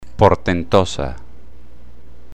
Common Name:    Madagascar Hissing Cockroach
They hiss through tiny holes on the side of their bodies called spiracles.
Listen to them hiss!